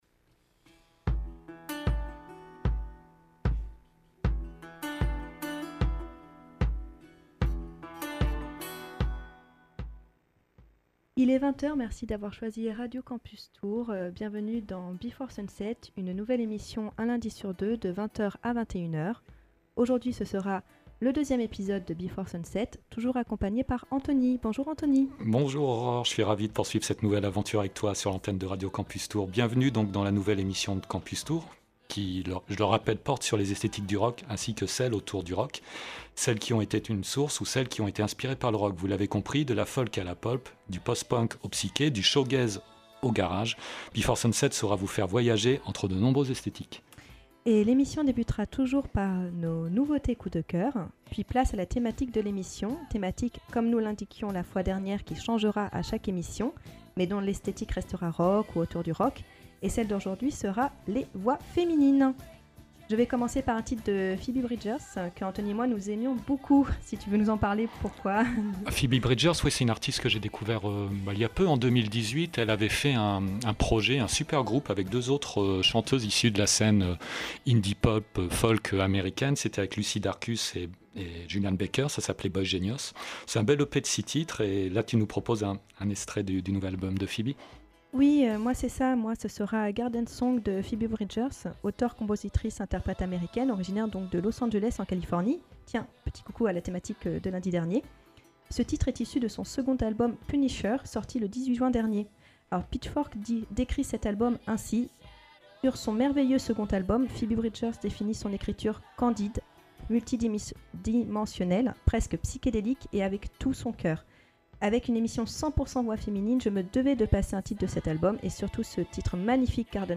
Le thème de notre seconde émission était Les voix féminines